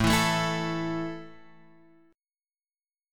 A5 chord {5 7 7 x 5 5} chord